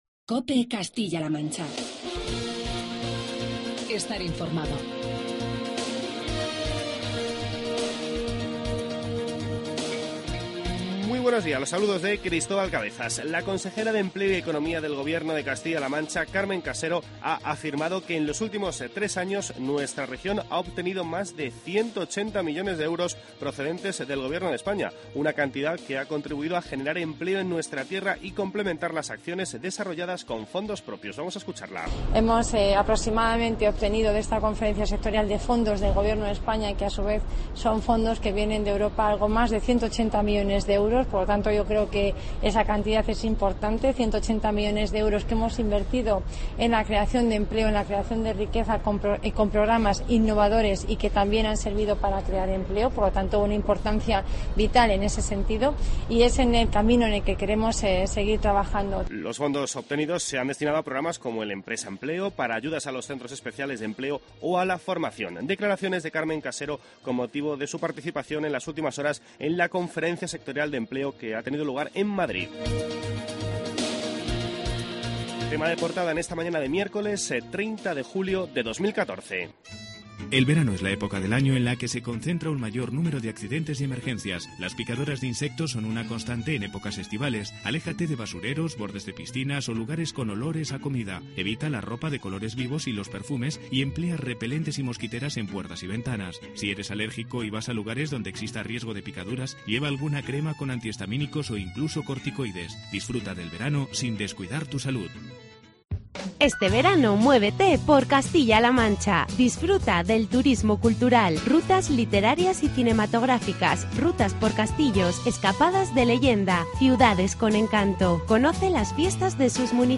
Informativo provincial
Escuchamos las palabras de Carmen Casero tras su participación en Madrid en la Conferencia Sectorial de Empleo